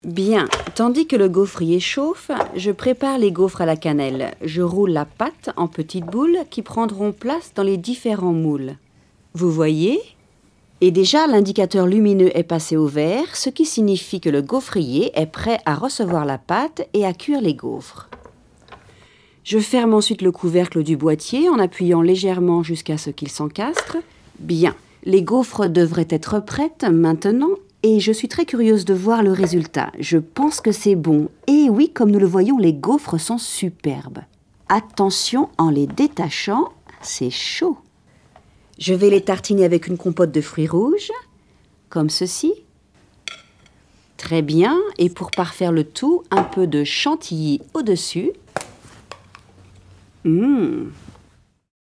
Deutsch und Englisch mit französischem Akzent. Elegante, romantische, dunkle Stimme.
Sprechprobe: Industrie (Muttersprache):
Native french voice in Berlin.
Elegant & romantic voice.